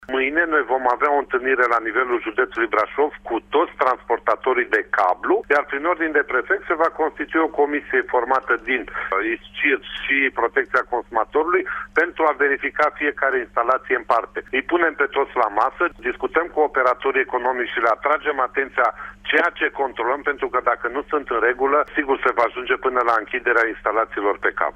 Prefectul Brașovului Mihai Mohaci: